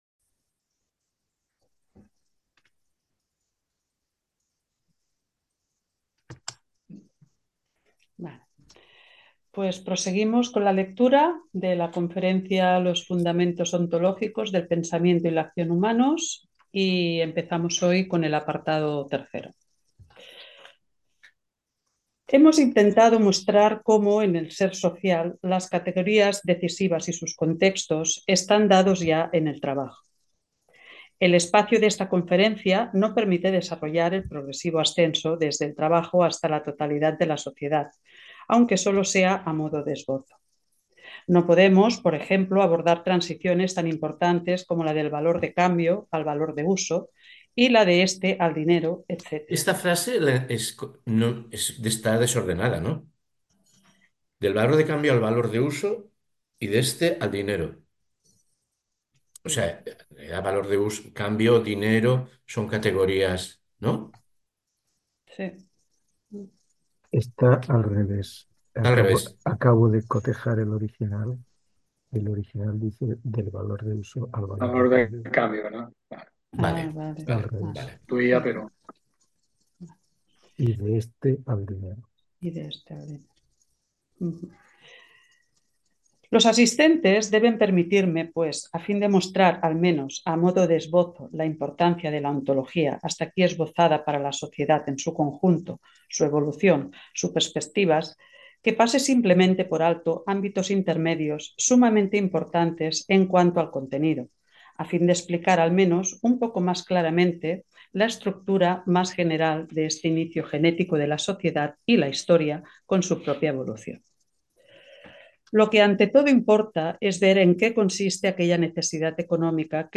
El jueves 31 de octubre de 2024 nuestra asociación Espaimarx inició un nuevo seminario de lectura de textos.
La forma de proceder es leer anticipadamente unas 20 o 25 páginas de texto, que posteriormente son releídas y comentadas en una puesta en común, que dirige un monitor.